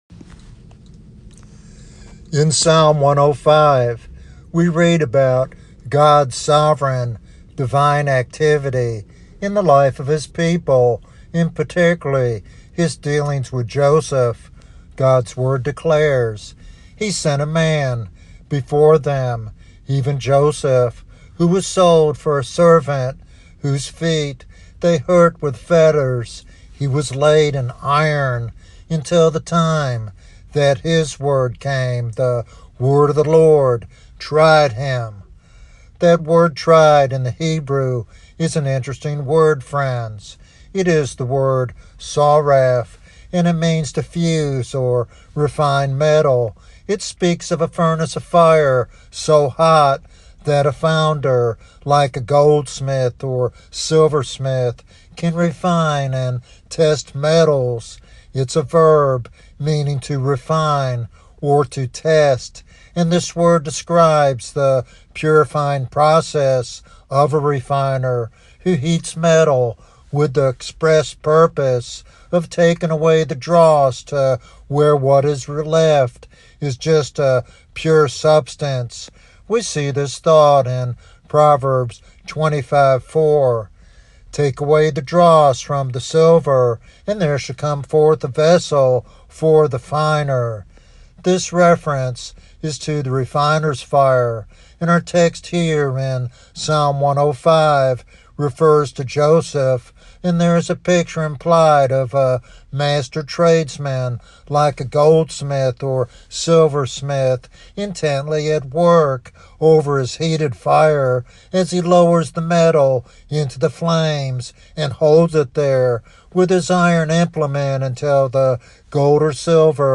This devotional sermon offers hope and practical guidance for those enduring difficult seasons, emphasizing God's sovereign care and the believer's role in cooperating with His refining work.